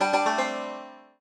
banjo_gd1gd1ac1.ogg